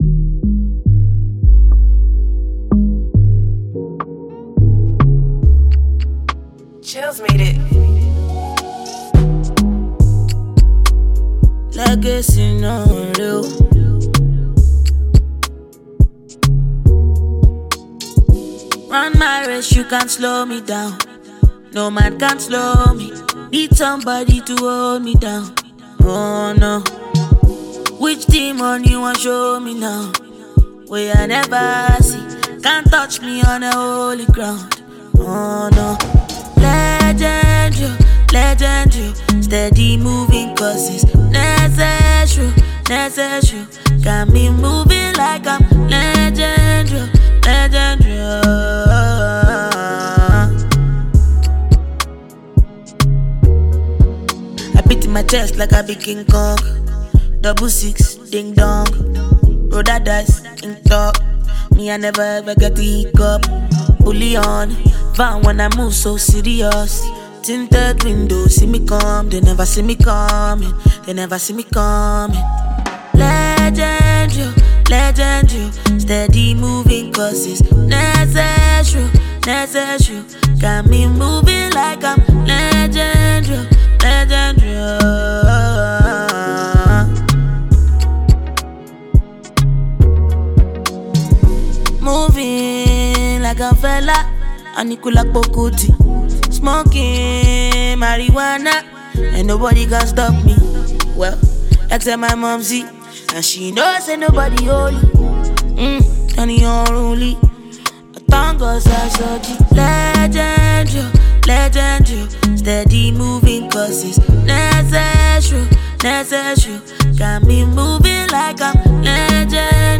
Najia Afrobeat